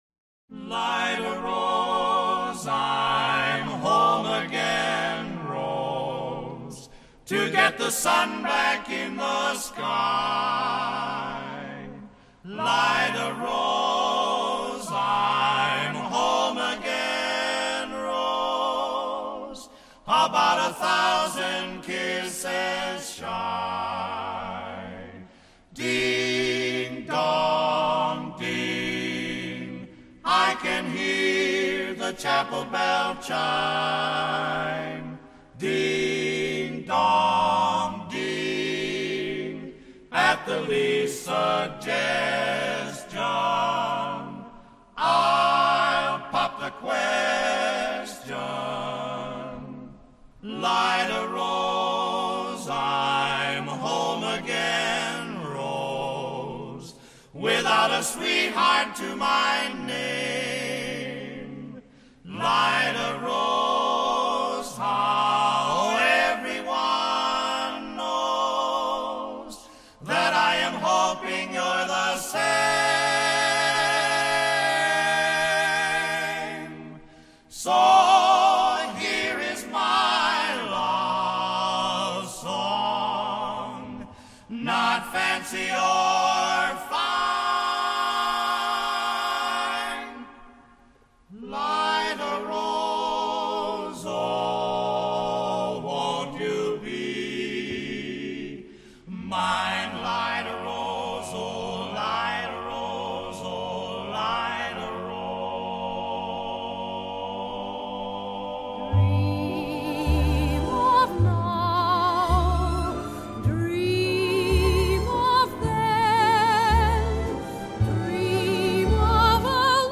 duet (barbershop quartet and soprano